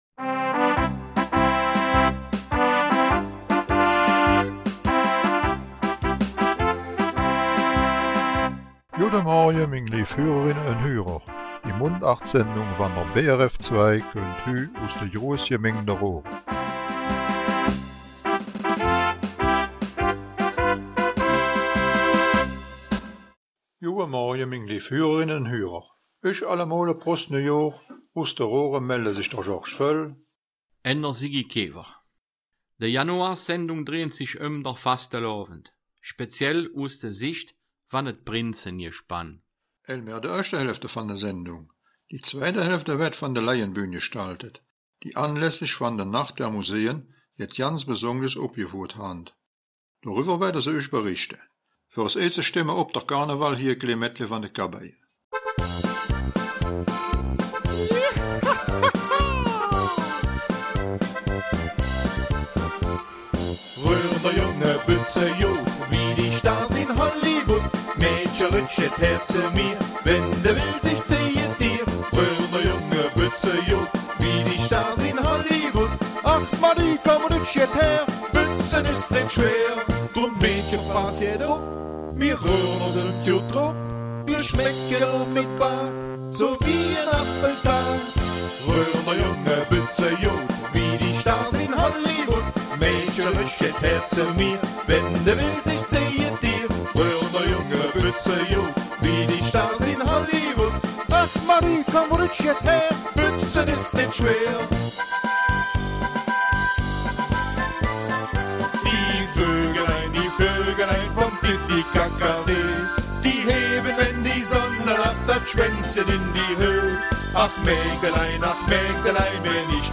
Die zweite Hälfte der Januar-Sendung wird traditionsgemäß von der Raerener Laienbühne gestaltet, die leider auch in diesem Jahr wegen dem ''fiesen Virus'' keine Aufführungen machen kann. Aber sie hat sich etwas Besonderes einfallen lassen und erzählt die Geschichte des Raerener Steinzeugs aus der Sicht der Töpfer, der Fuhrleute, der Händler und den Benutzern des Steingutes.